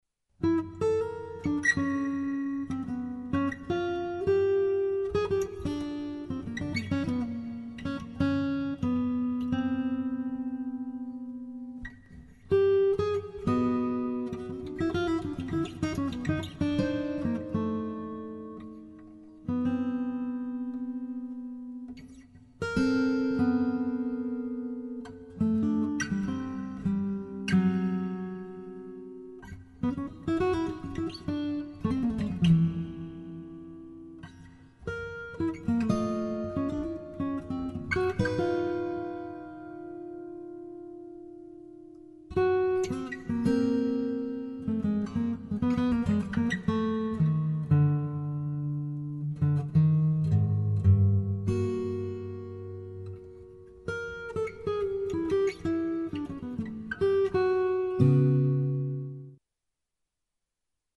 for 6-string and extended baritone guitar duet